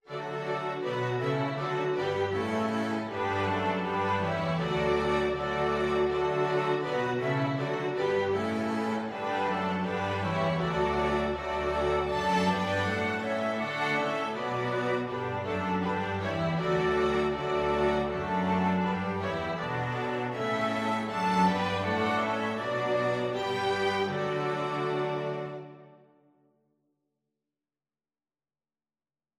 Christmas Christmas Flexible Mixed Ensemble
FluteTrumpetViolinGuitar Lead Sheet (Chords)
BassoonDouble BassTromboneCello
Allegro = c.80 (View more music marked Allegro)
2/2 (View more 2/2 Music)